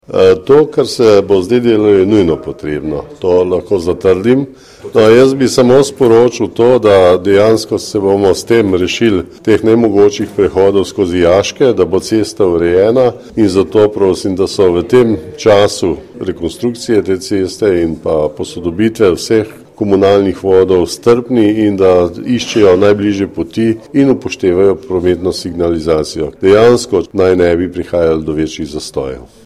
Župan Alojzij Muhič o pomenu projekta za Novo mesto